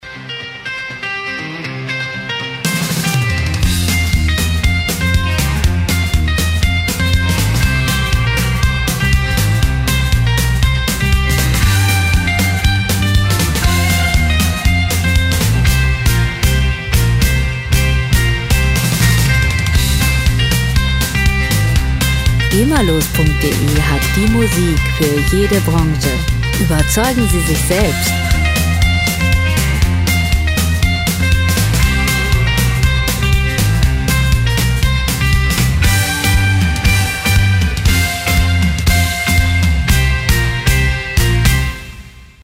Musikstil: Rock 'n' Roll
Tempo: 240 bpm
Tonart: C-Dur
Charakter: ungezähmt, rasant